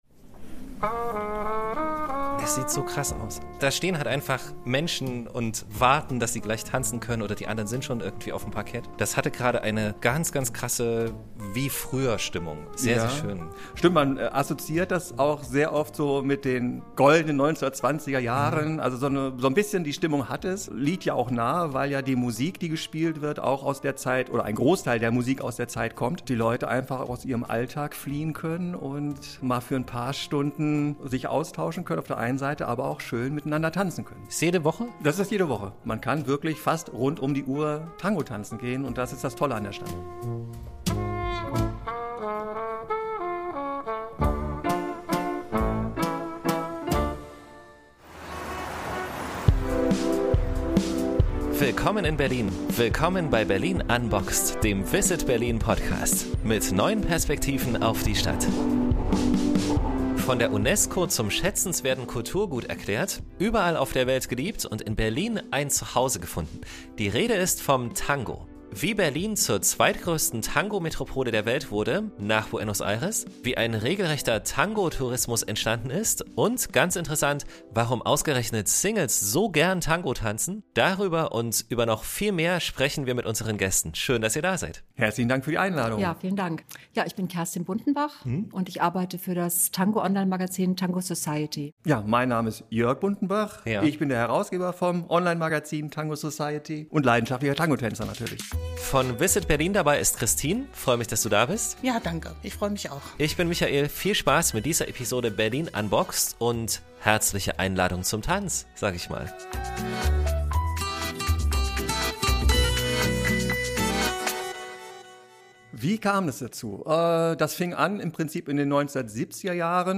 Interviewpartner